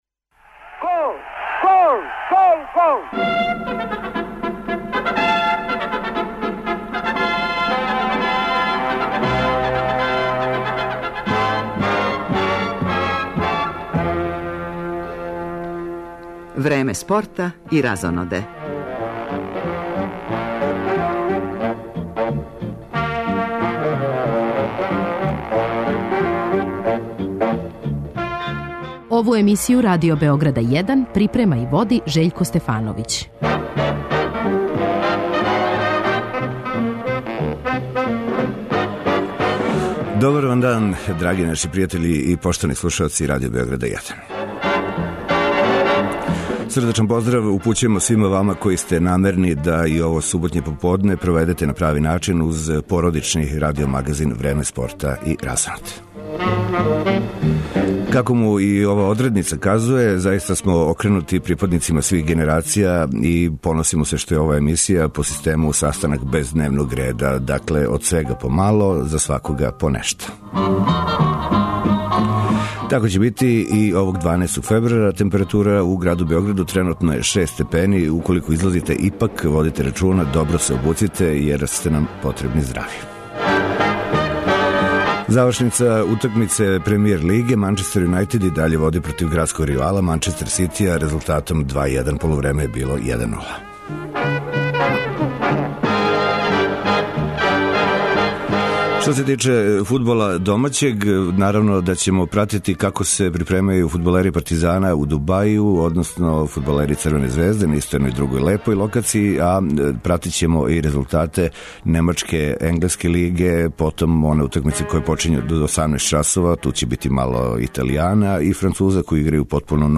Поводом 40 година уметничког рада, гост у студију Радио Београда 1, је врсни певач забавне музике Лео Мартин.